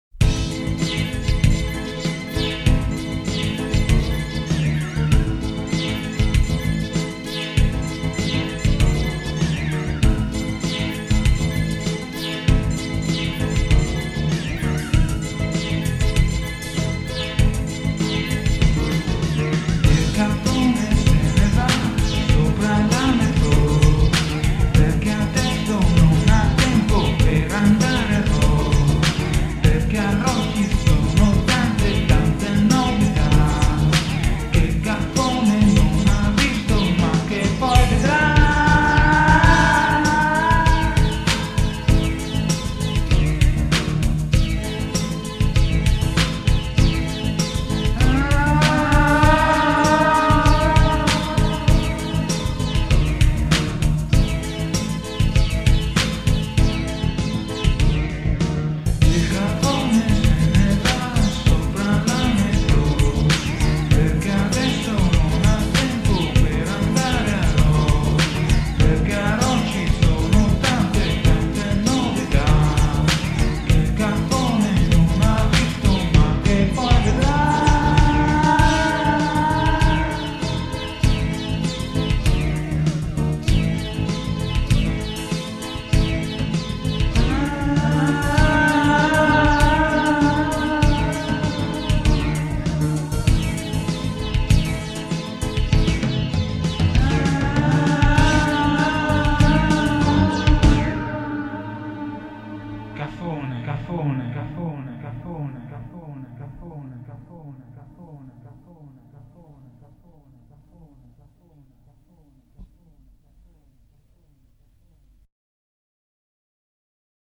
vocals
keyboard